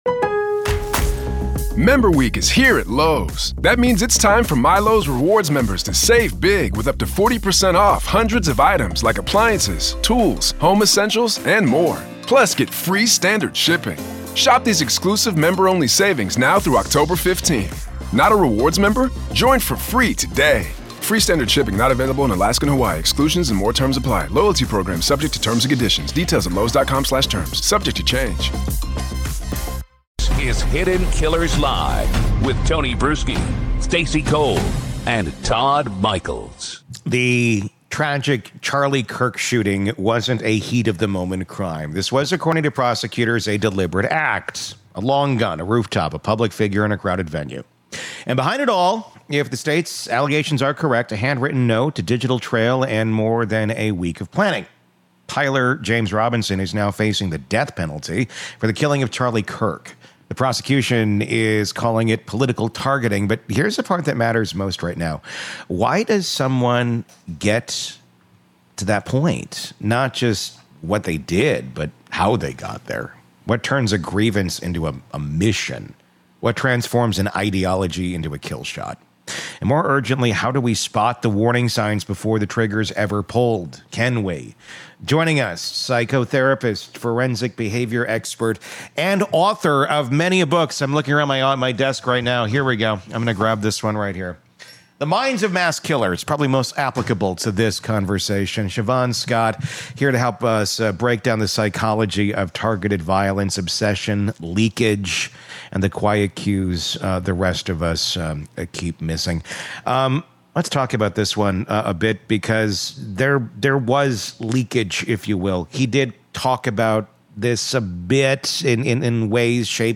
In this interview, we explore: